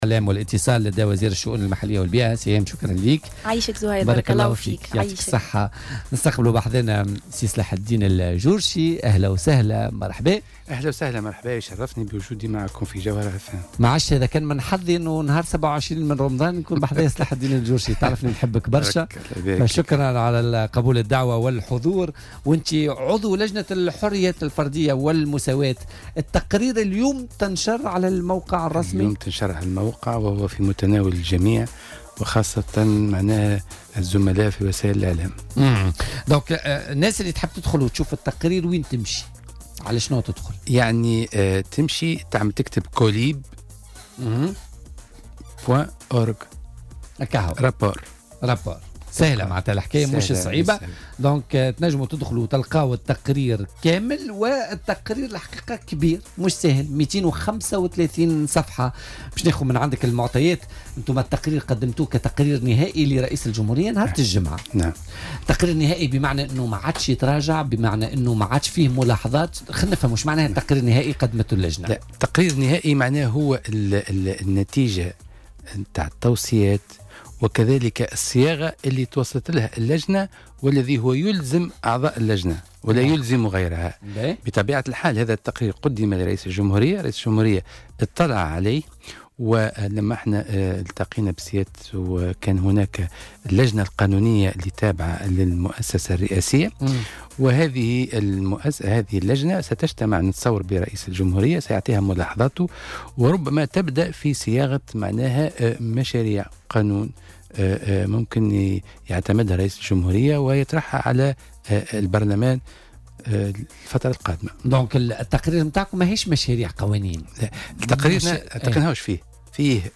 وقال ضيف "بوليتيكا" على "الجوهرة اف أم" في استديو تونس العاصمة إنه لأول مرة يتم التطرق إلى الحريات الفردية من طرف مختصين مشيرا إلى ان التقرير يعتبر خطوة نوعية تنفرد بها تونس لبناء نظام ديمقراطي قائم على الحريات.